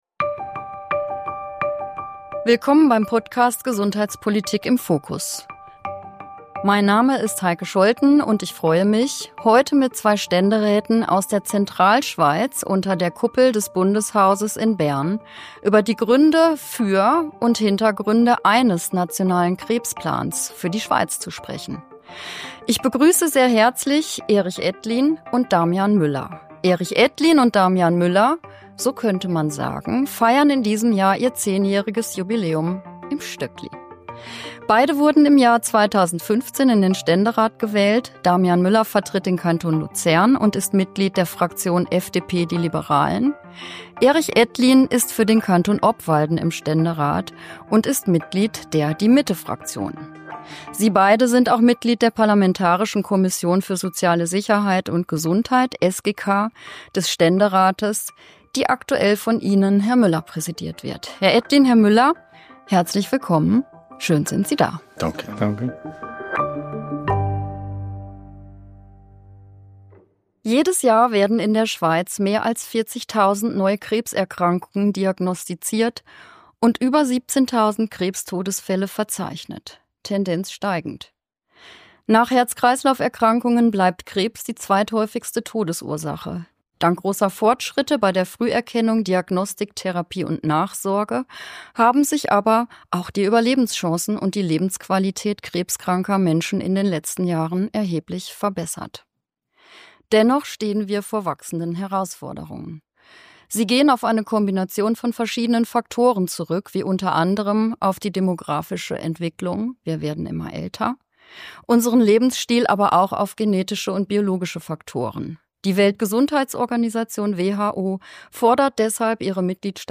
mit den Ständeräten Erich Ettlin und Damian Müller Der aktuelle Podcast beleuchtet die Dringlichkeit eines Nationalen Krebsplans für die Schweiz.